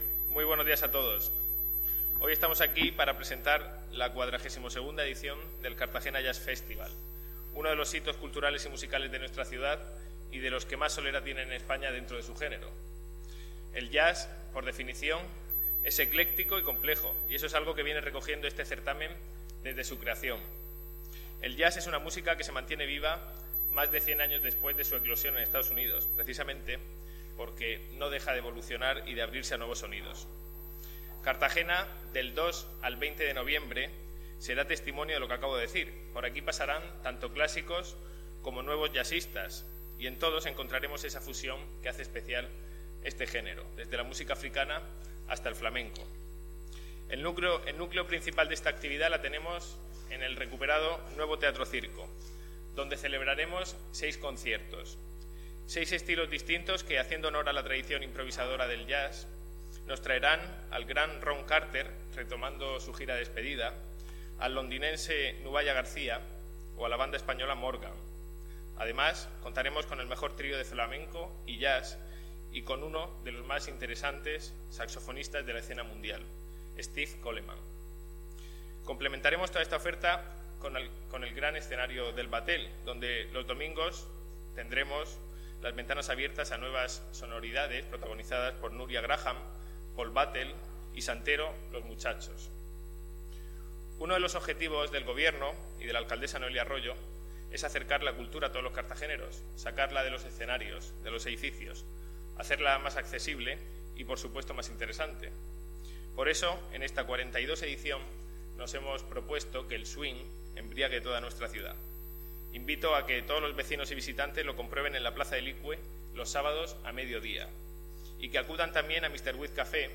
Enlace a Delcaraciones del concejal Nacho Jáudenes en la presentación del Cartagena Jazz Festival